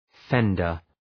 Shkrimi fonetik {‘fendər}